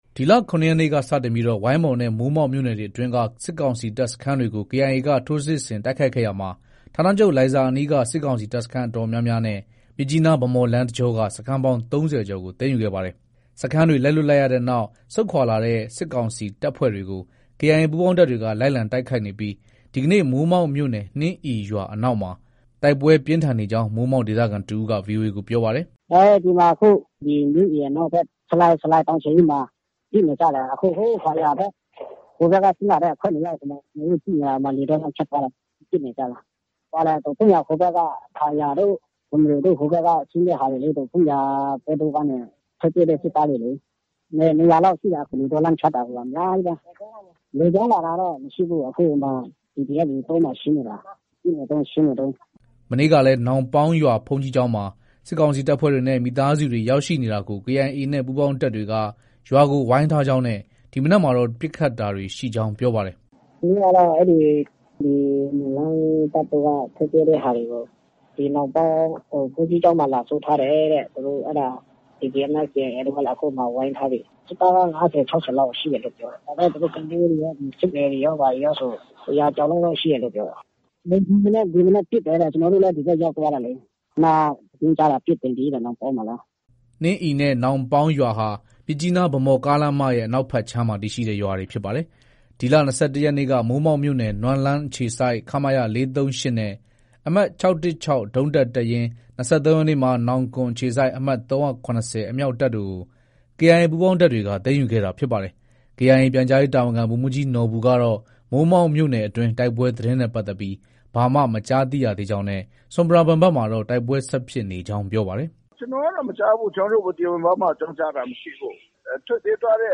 ကချင်ပြည်နယ်အတွင်း တိုက်ပွဲတွေ ပြင်းထန်နေချိန်မှာပဲ ရှမ်းပြည်နယ်တောင်ပိုင်း ပအိုဝ်းကိုယ်ပိုင်အုပ်ချုပ်ခွင့်ရ ဒေသ ဆီဆိုင်မြို့မှာလည်း စစ်ကောင်စီတပ်နဲ့ ပအိုဝ်းအမျိုးသားလွတ်မြောက်ရေးတပ် PNLA အကြား တိုက်ပွဲတွေက ဆက်လက်ဖြစ်ပွားနေပါတယ်။ လက်ရှိ တိုက်ပွဲဖြစ်ပွားနေတဲ့အခြေအနေကို ဒေသခံတဦးက အခုလို ပြောပါတယ်။